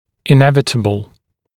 [ɪ’nevɪtəbl][и’нэвитэбл]неизбежный, неминуемый